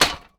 pgs/Assets/Audio/Metal/metal_hit_small_08.wav
metal_hit_small_08.wav